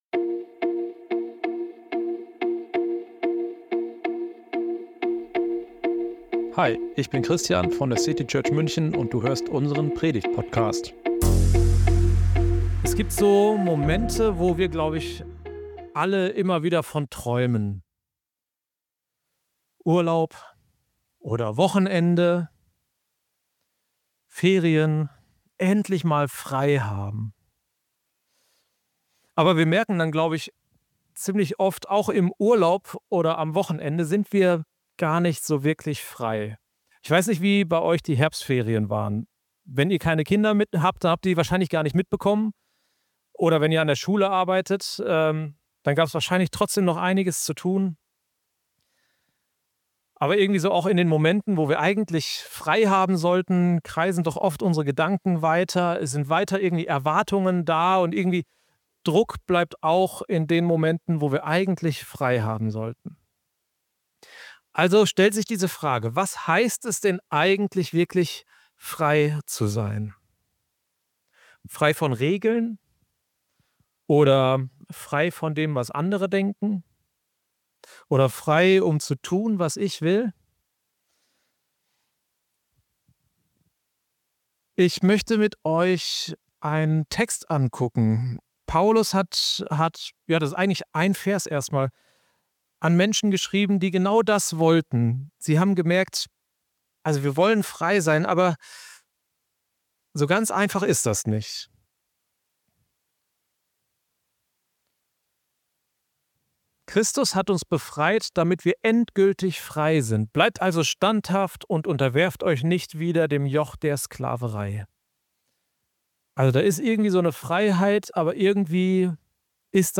In dieser Predigt entdecken wir, was das heute bedeutet – mitten im Alltag.